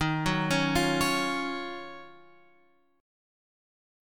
D# Minor 9th